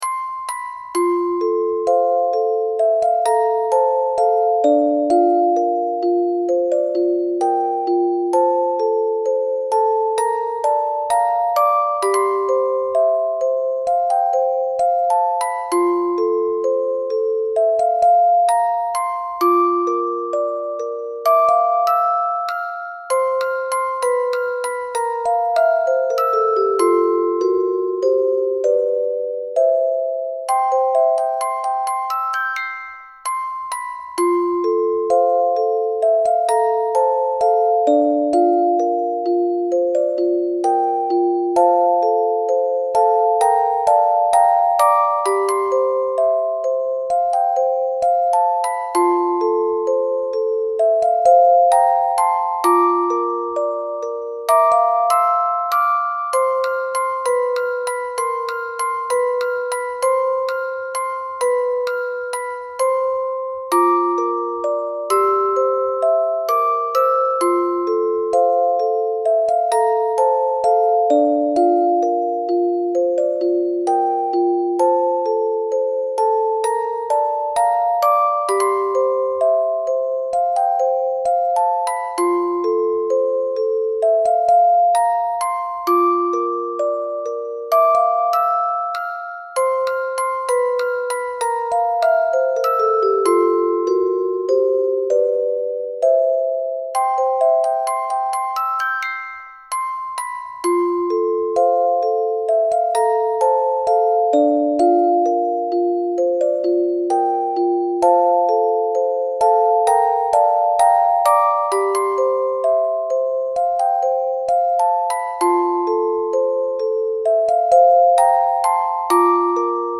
そんな無垢なコビトたちへのオルゴール♪